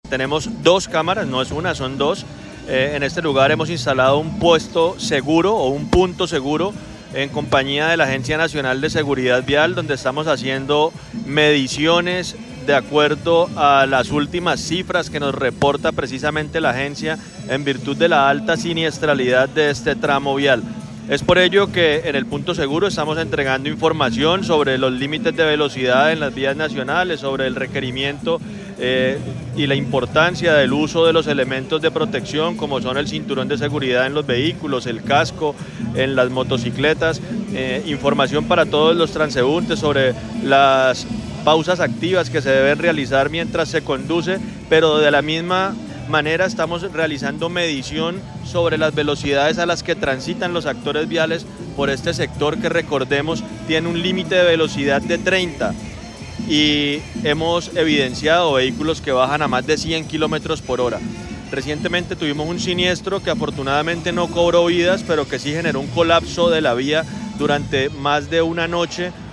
Juan José Gómez, secretario de Tránsito de Girón